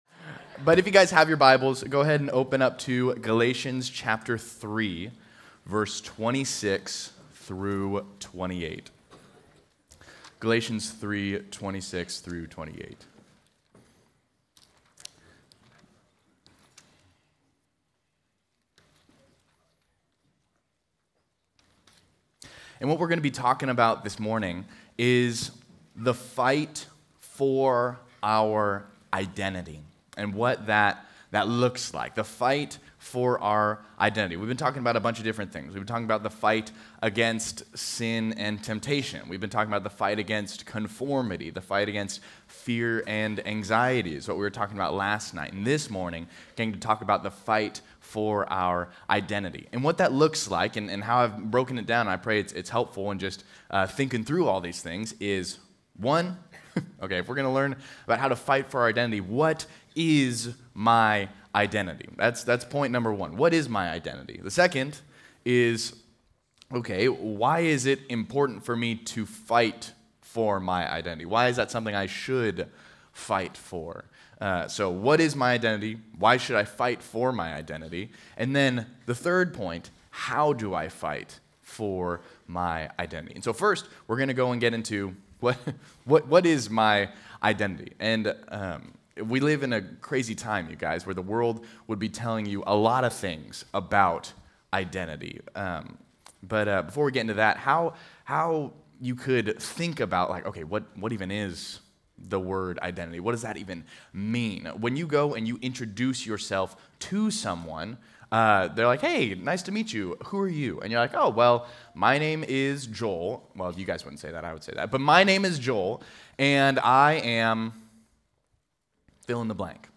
Home » Sermons » The Fight for our Identity